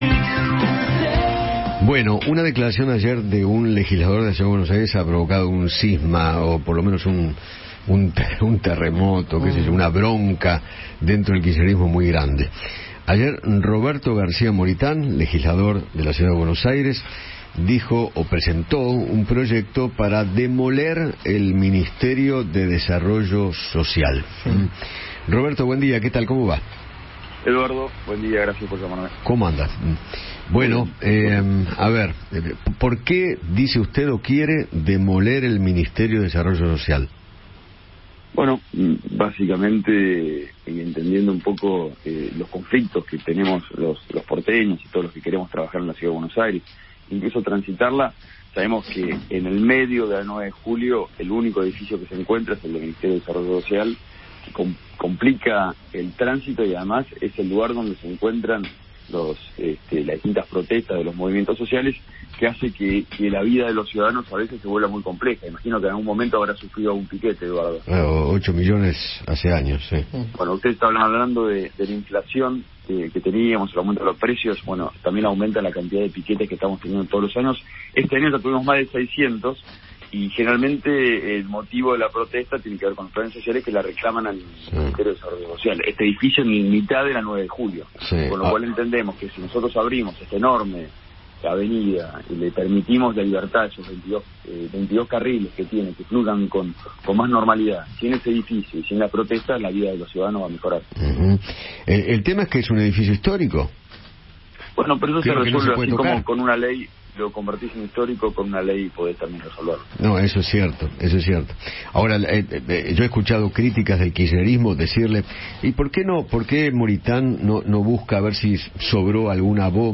Roberto García Moritán, legislador porteño, dialogó con Eduardo Feinmann sobre la propuesta que realizó para demoler el edificio de Desarrollo Social.